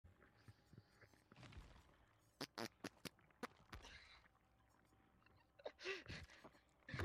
farty-lad.mp3